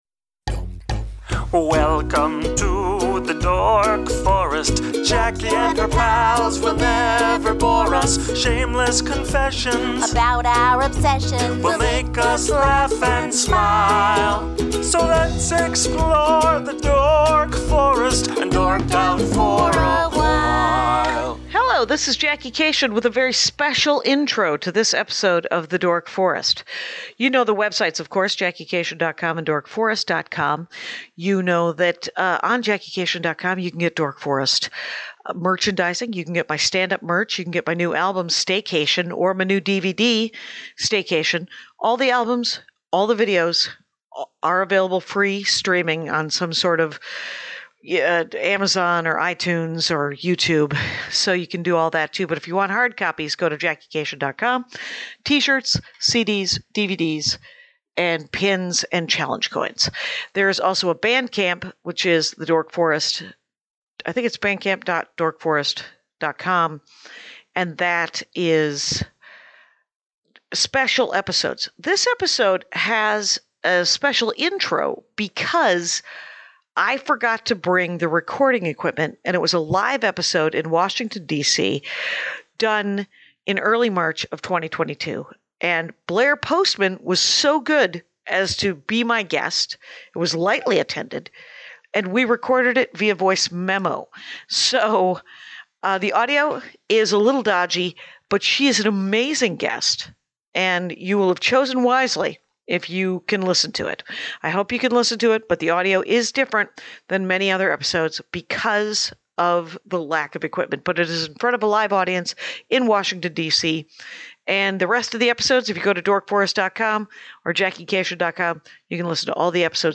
This one is LIVE.
The audio is Voice MEMO bc I forgot equipment and that i was doing it live and so there’s like 10 people in the room.